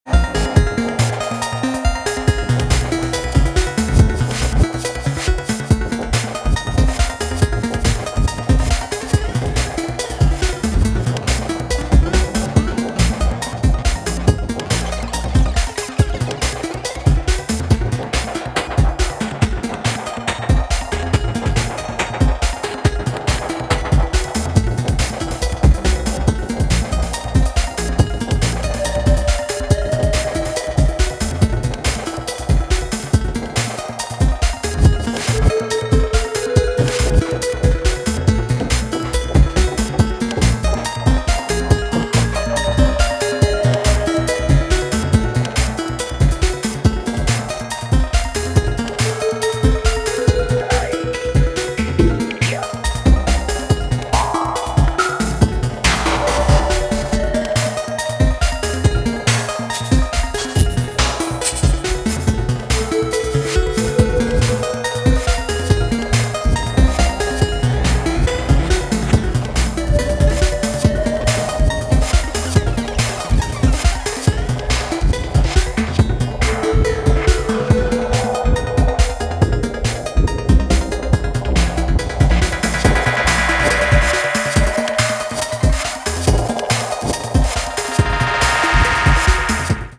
Genre Electro , Techno